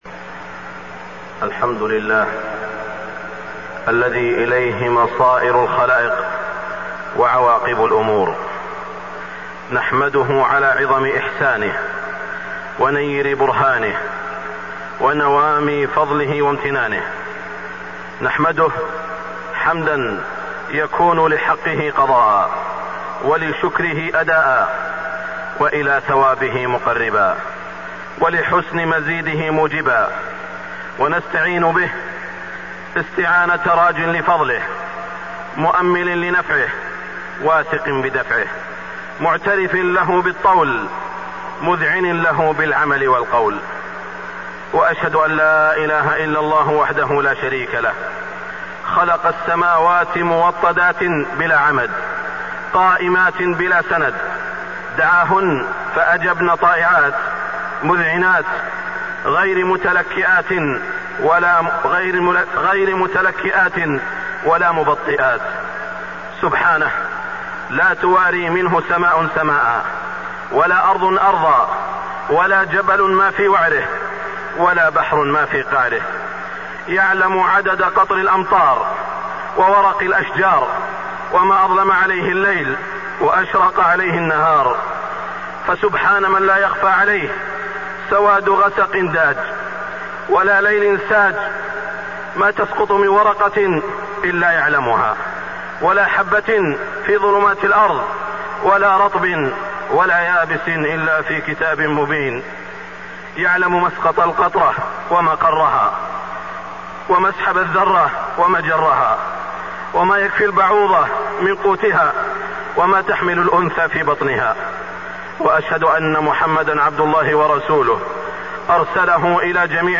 خطبة الإستسقاء 6-1-1425هـ > خطب الاستسقاء 🕋 > المزيد - تلاوات الحرمين